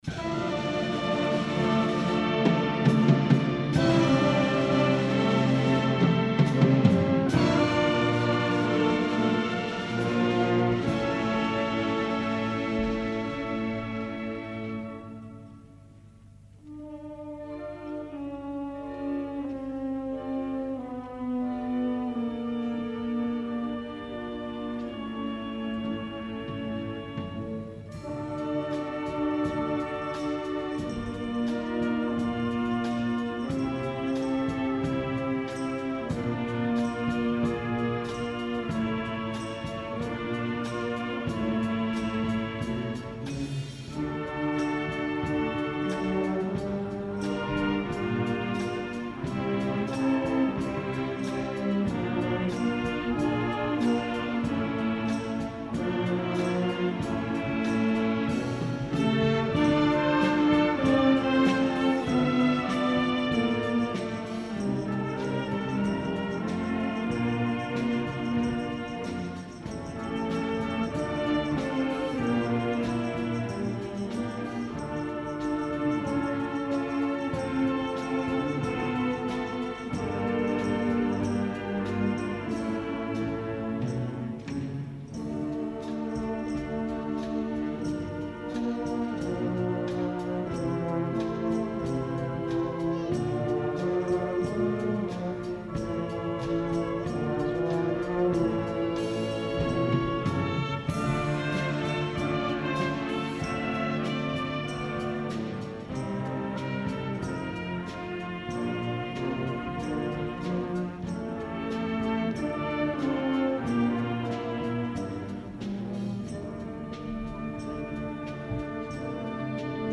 Concert de Santa Cecília a l'Esglèsia de la Nostra Senyora de la Consolació